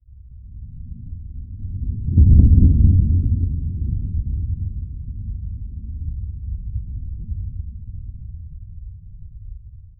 Trueno retumbando a lo lejos
trueno-retumbando-a-lo-le-dkiea26k.wav